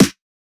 Index of /99Sounds Music Loops/Drum Oneshots/Twilight - Dance Drum Kit/Snares